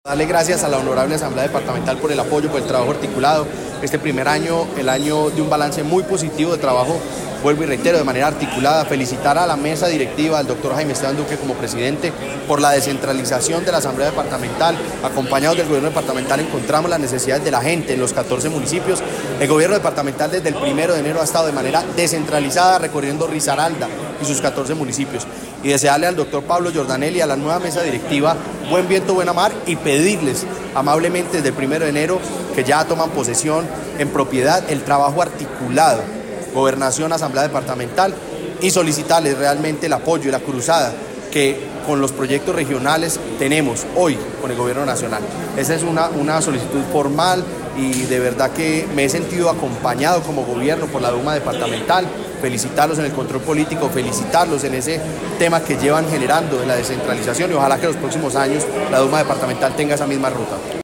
En el marco de la clausura de las sesiones ordinarias de la Asamblea Departamental de Risaralda, el gobernador Juan Diego Patiño entregó un balance de su gestión durante el primer año de gobierno, al tiempo que enfatizó los desafíos en materia de seguridad y los riesgos derivados de los recortes presupuestales anunciados por el Gobierno Nacional.
JUAN-DIEGO-PATINO-OCHOA-GOBERNADOR-RISARALDA-3.mp3